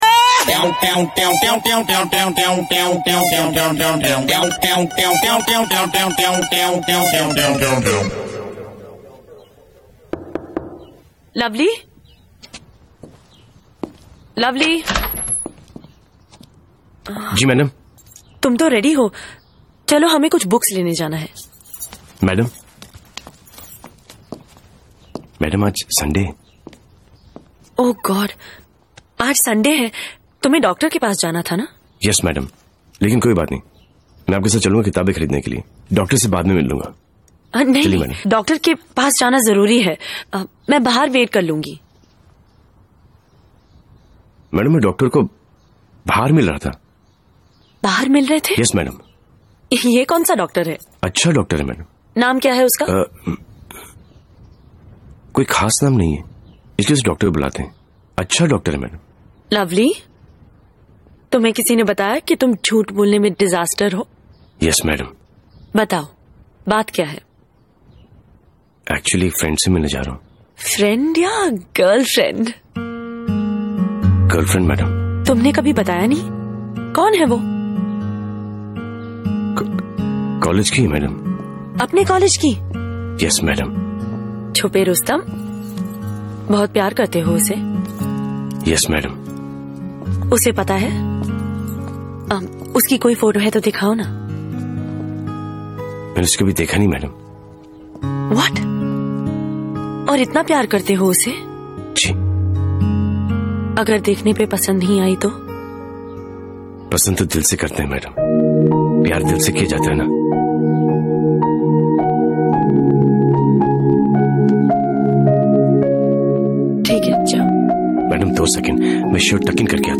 Salman Khan best movie scene sound effects free download